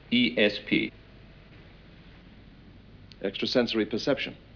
ESP sound effect.wav